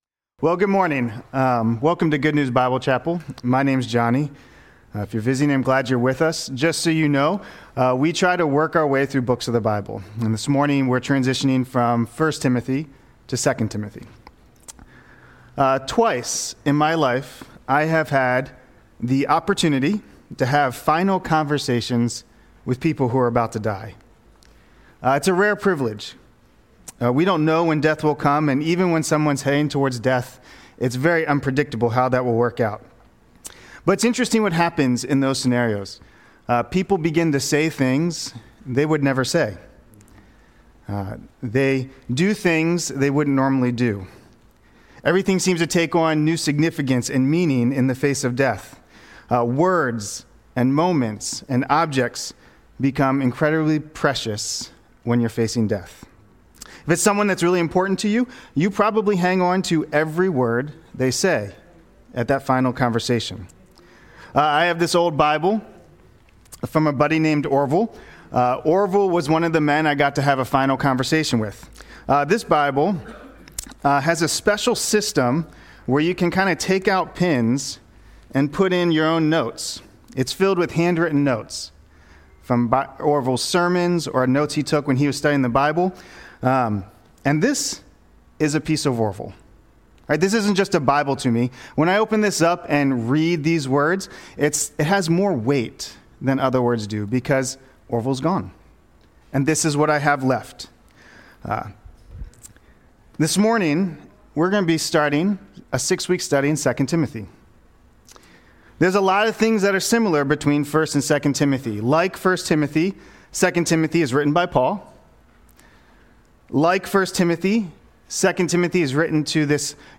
Service Type: Celebration & Growth